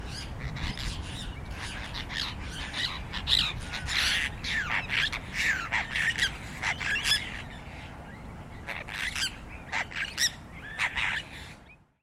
Gimpelhäher (Apostelbird) und ihr "Geschwätz".